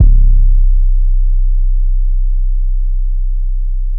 MB SO ICEY 2 808 (3).wav